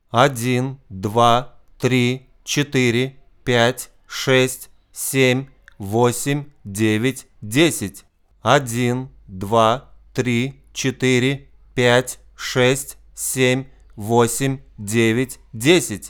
- Послушайте разницу в звучании микрофона ntk с родной корзиной и с сеткой от сита для муки, которую Вы тут показали. Обычная сетка для муки из обычной стали похуже экранирует капсюль от наводок 50 Гц, это можно увидеть на графике спектра второго фрагмента в любом звуковом редакторе. Первый фрагмент - абсолютно всё в оригинале, второй фрагмент следом - сетка от сита для муки.